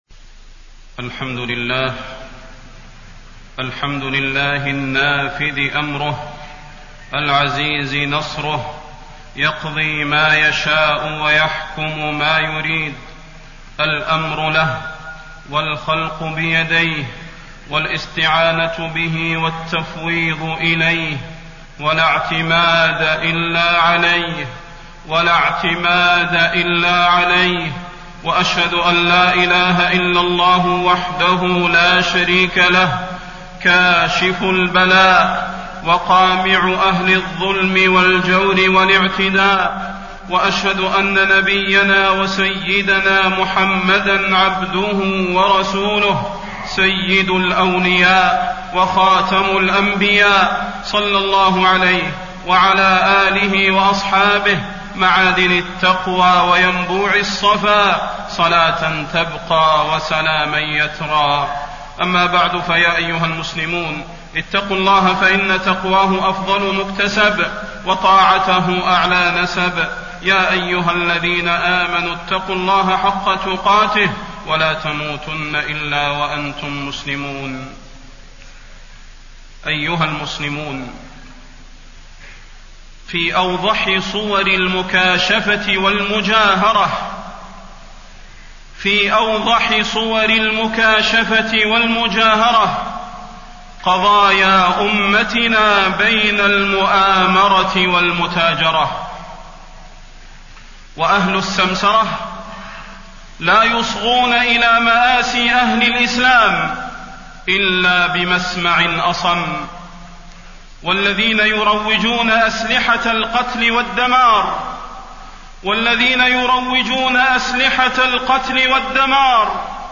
فضيلة الشيخ د. صلاح بن محمد البدير
تاريخ النشر ١٦ ربيع الثاني ١٤٣٣ هـ المكان: المسجد النبوي الشيخ: فضيلة الشيخ د. صلاح بن محمد البدير فضيلة الشيخ د. صلاح بن محمد البدير الجرح النازف الشام